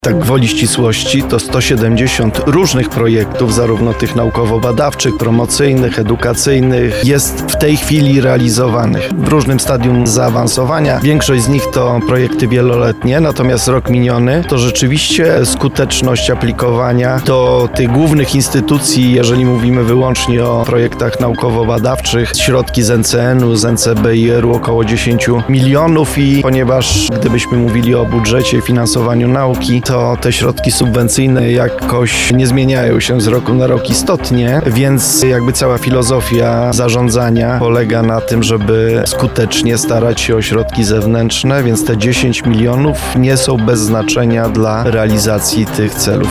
prof. Radosław Dobrowolski – tłumaczy Rektor UMCS.